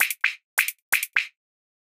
130_HH_FX_LP_1.wav